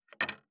589まな板の上,包丁,ナイフ,調理音,料理,
効果音厨房/台所/レストラン/kitchen食器食材